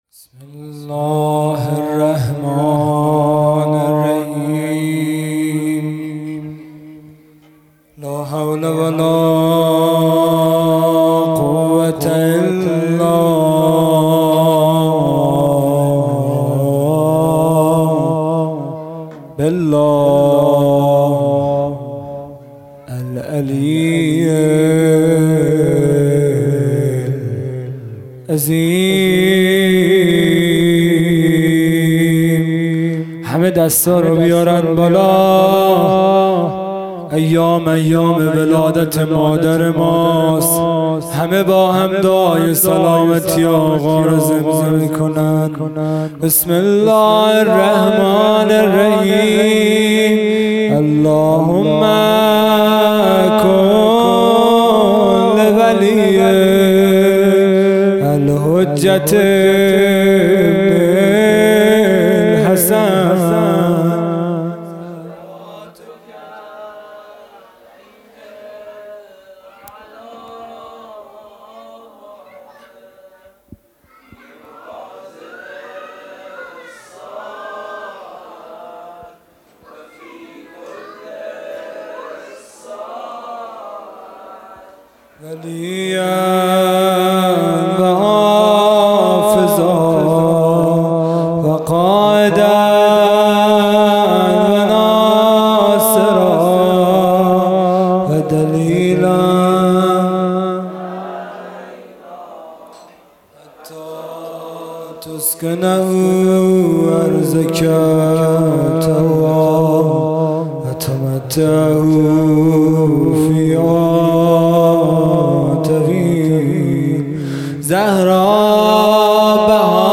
خیمه گاه - هیئت بچه های فاطمه (س) - مدح | زهرا بهانه ایست که عالم بنا شود | 30 دی 1400
جلسۀ هفتگی | ولادت حضرت زهرا(س)